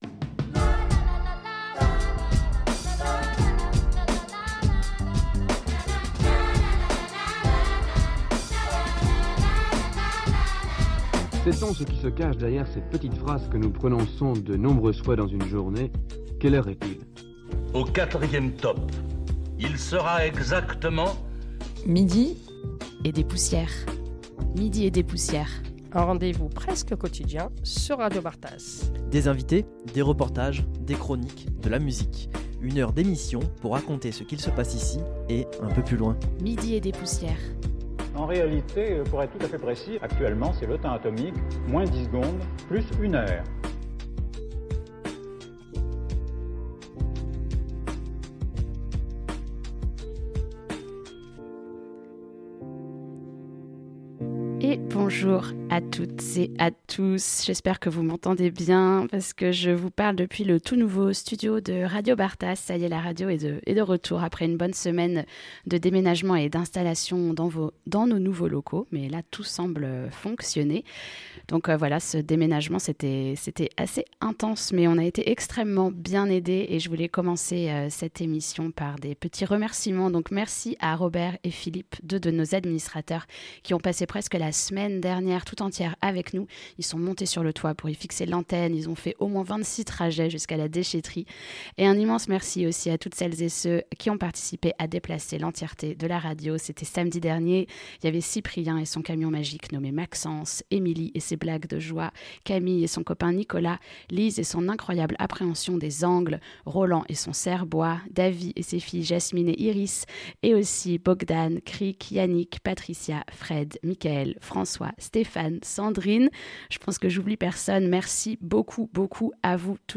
Toute première émission en direct depuis les nouveaux studios de radio Bartas